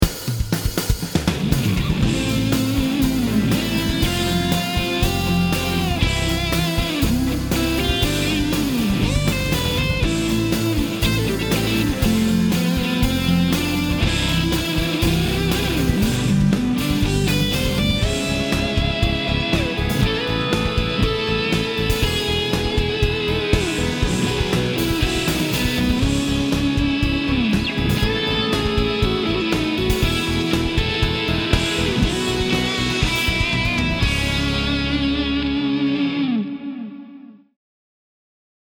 Sooloesimerkki:
Tällä kertaa soolo on poptyylinen.
Soolon komppi etenee neljän soinnun kierrolla, joka on:
Am | F | C| G x2
Kahden sointukierron jälkeen seuraa modulaatio, eli siirrymme toiseen sävellajiin.
C#m | A | E | B x2